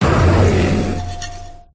pokerogue / public / audio / cry / 1020.ogg